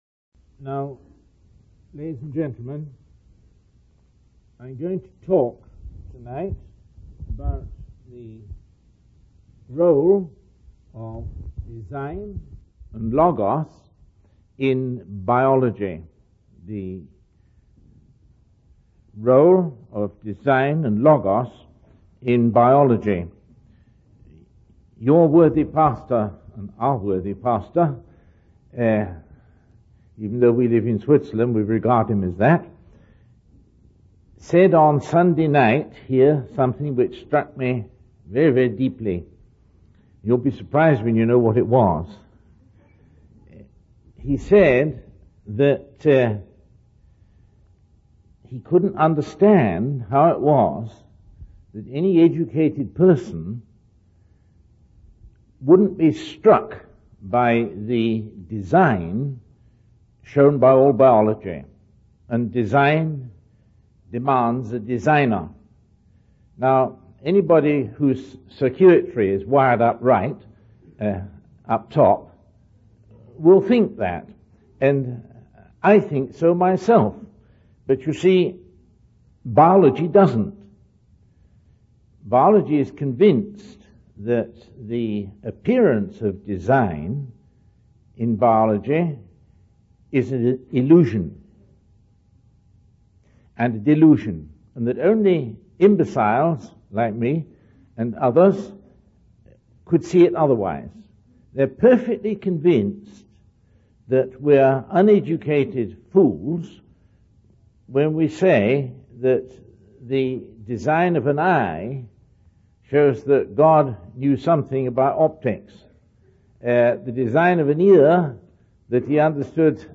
In this sermon, the speaker discusses the concept of design and logos in biology.